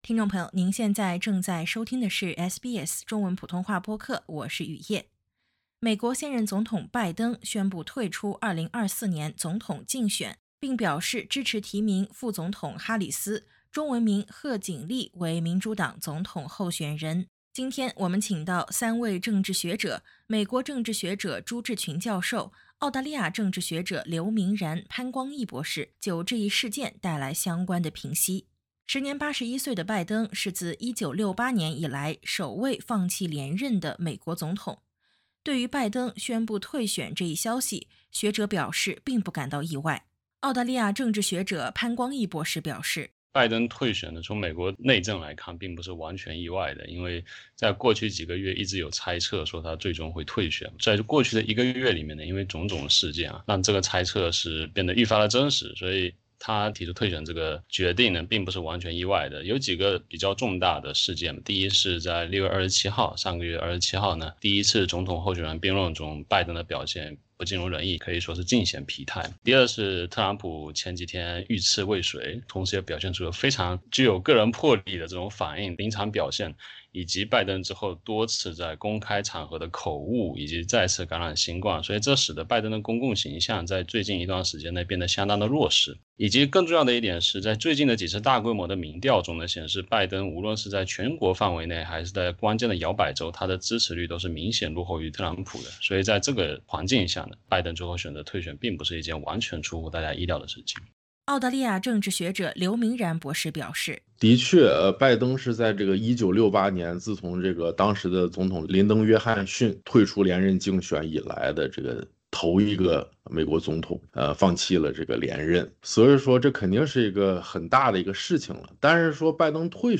点击音频，收听三位政治学者的相关评析。